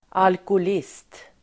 Uttal: [alkohål'is:t]